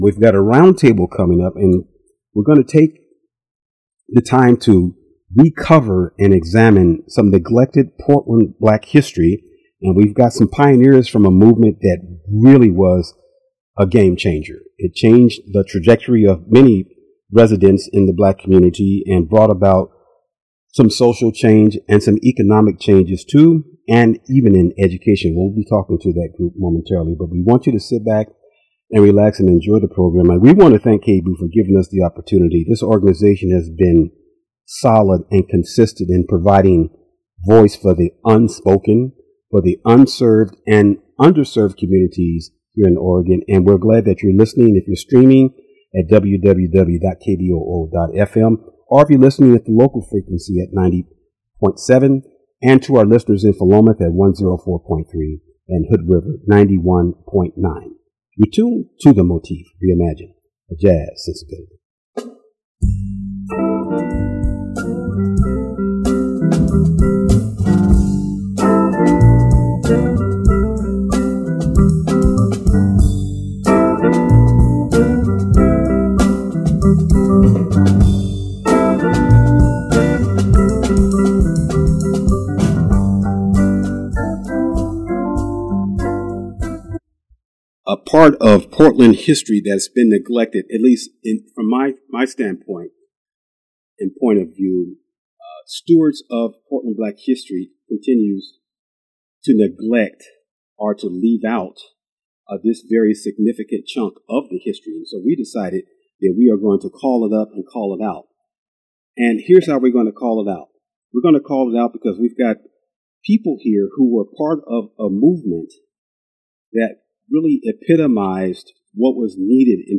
nation_of_islam_roundtable_mixdown.mp3